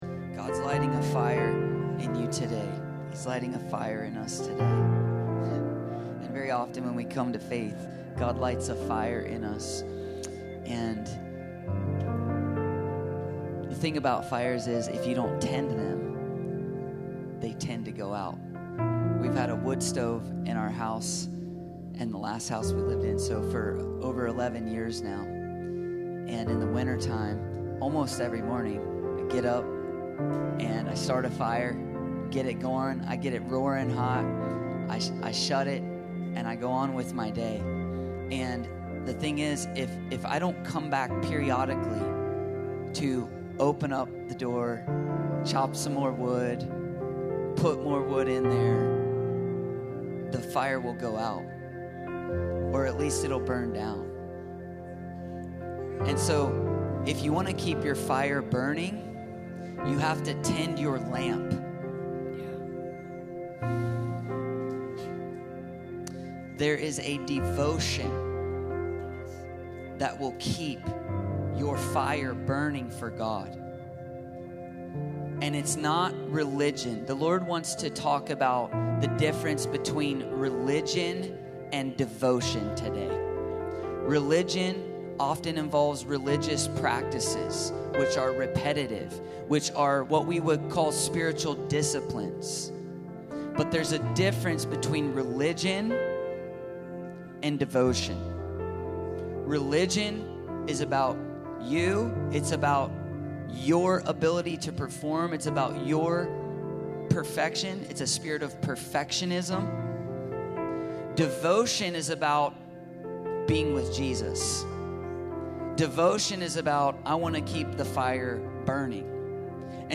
Free People Church: AUDIO Sermons